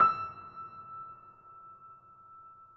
Steinway_Grand
e5.mp3